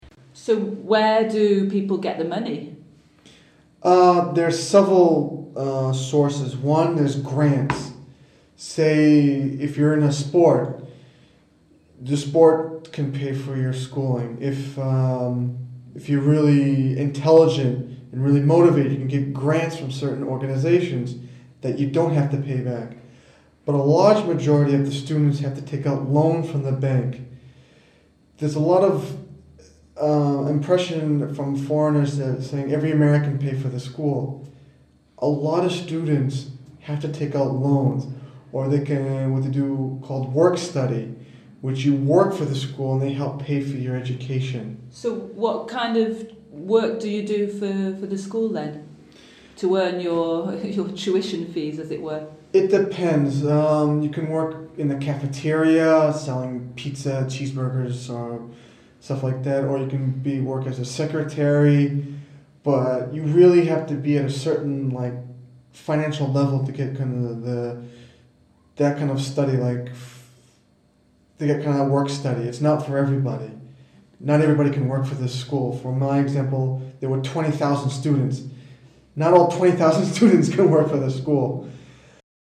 Britannique / Américain